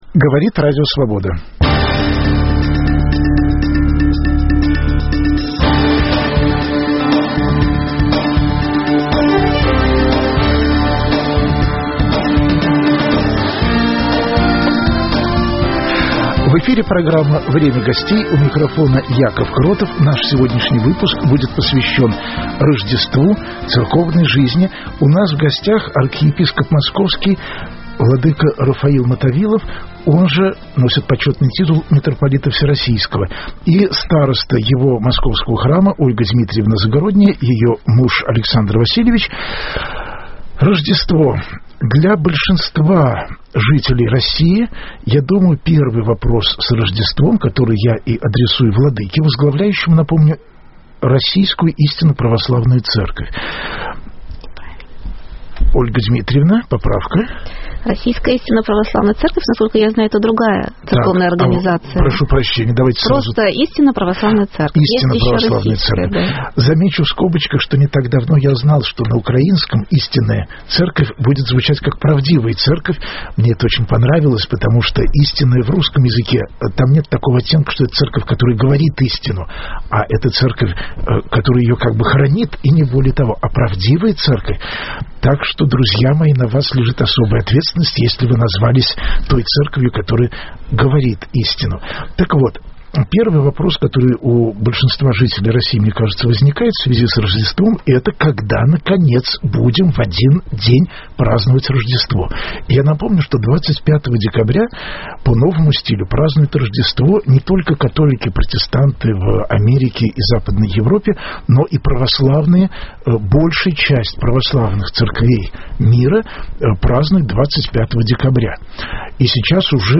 Известные и интересные люди ведут разговор о стране и мире, отвечают на вопросы в прямом эфире. Круг вопросов - политика, экономика, культура, права человека, социальные проблемы.